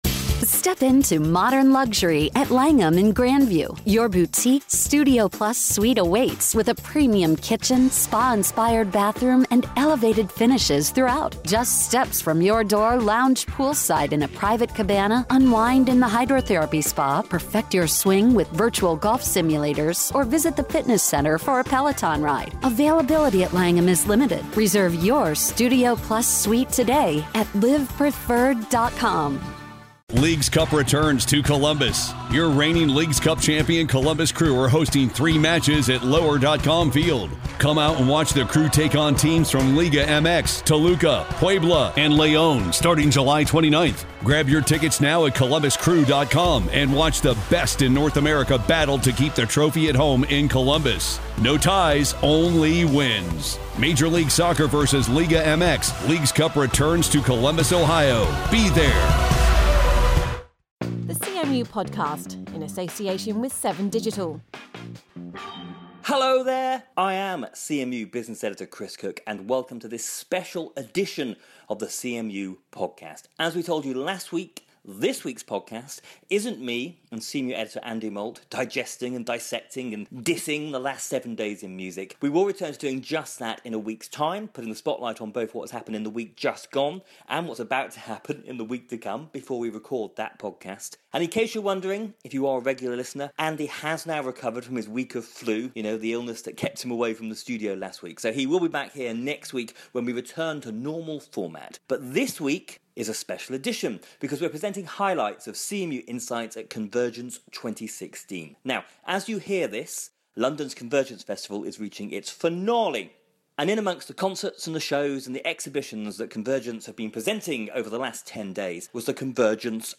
Last Thursday, CMU Insights presented a session at the Convergence festival considering the challenges facing the media sector in 2016.